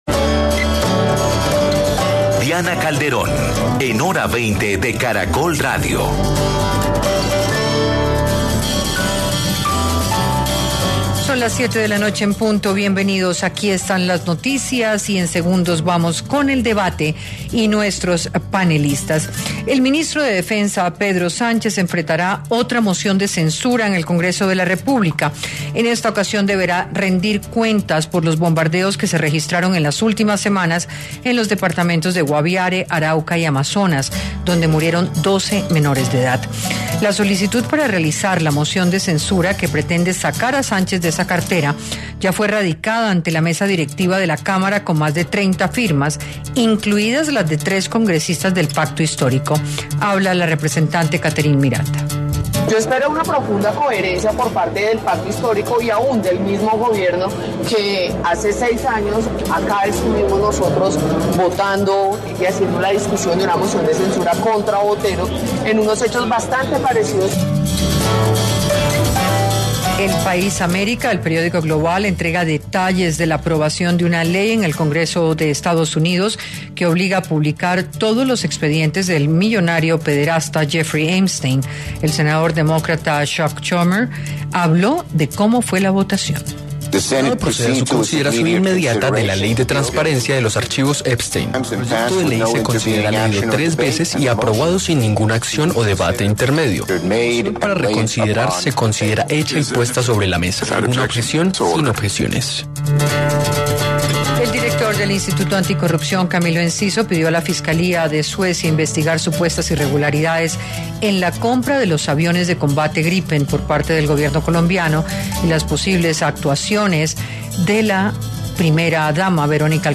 Panelistas analizaron el panorama con el que arranca la semana: el debate sobre los bombardeos en los que han muerto menores de edad y el incremento en la producción de cocaína.